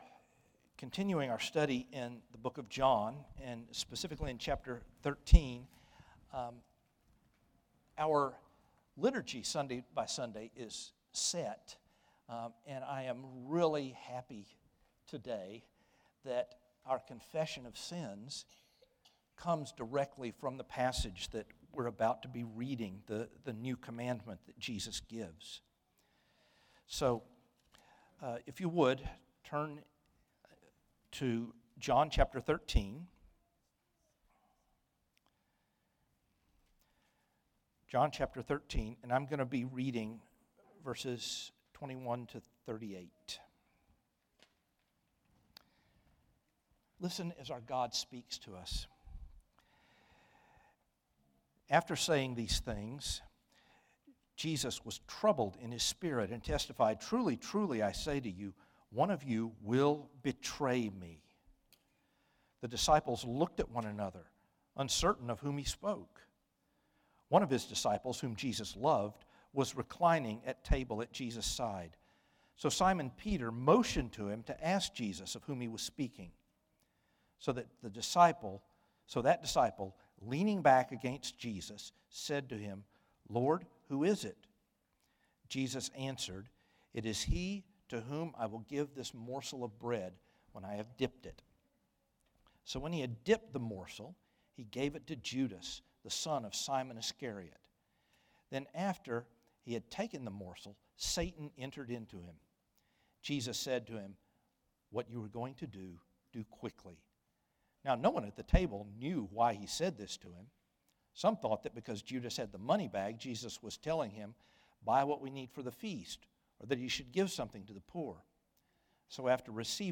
Sermons – Trinity Presbyterian Church